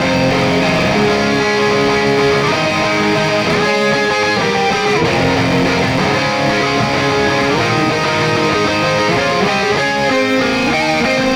右のギター(guitar2)のDRIVEを10上げてみます。